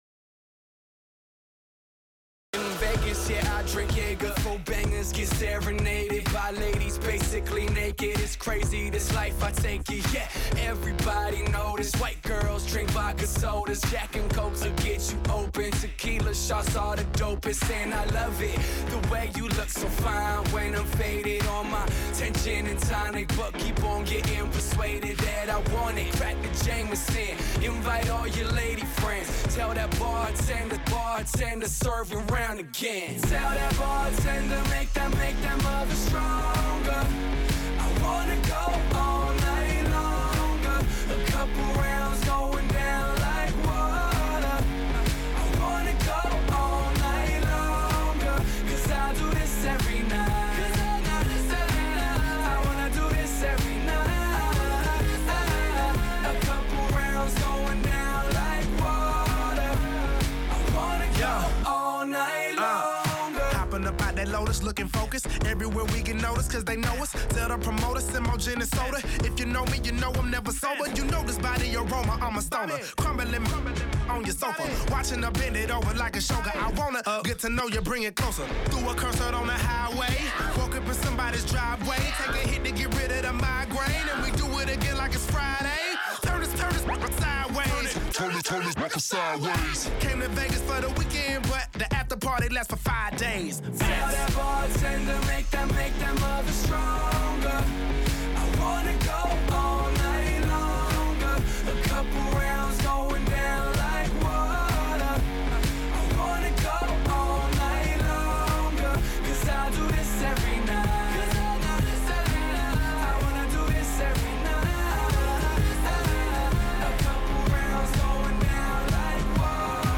A live dance, pop, and hip-hop mix